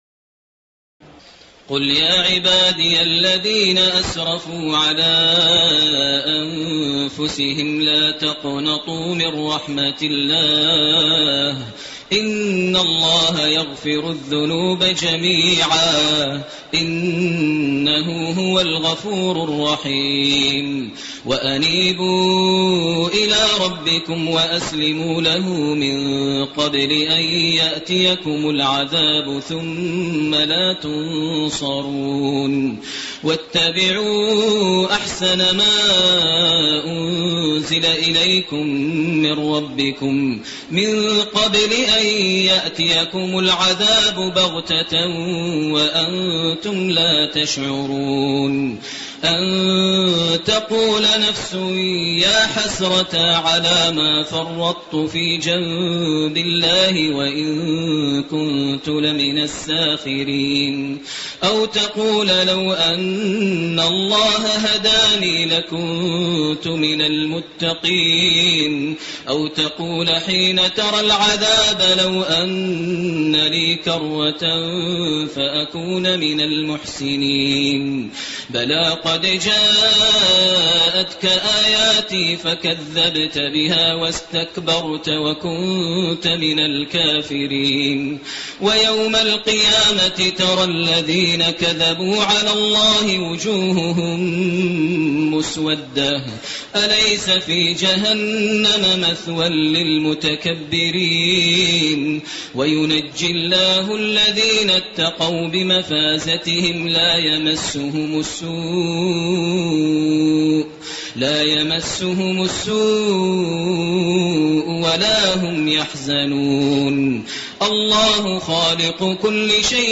تراويح ليلة 23 رمضان 1429هـ من سور الزمر (53-75) و غافر كاملة Taraweeh 23 st night Ramadan 1429H from Surah Az-Zumar and Ghaafir > تراويح الحرم المكي عام 1429 🕋 > التراويح - تلاوات الحرمين